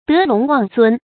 德隆望尊 dé lóng wàng zūn 成语解释 见“德隆望重”。